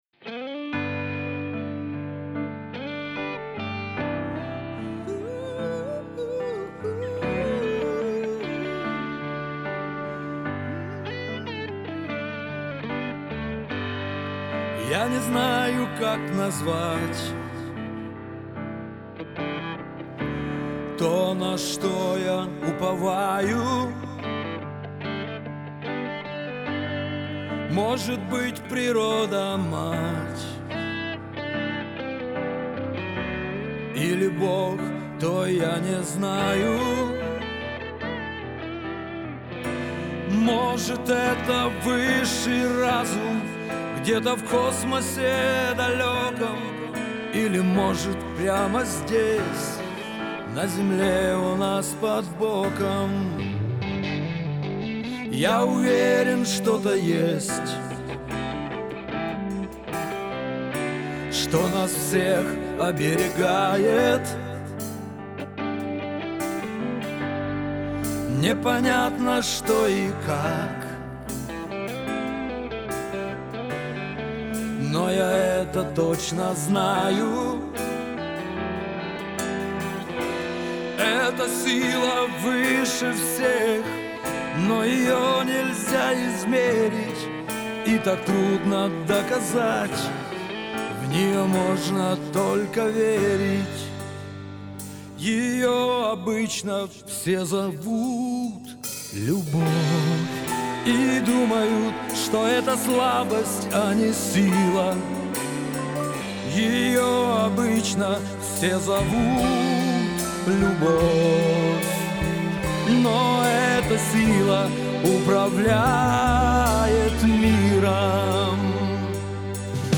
это яркая поп-песня, наполненная эмоциями и страстью.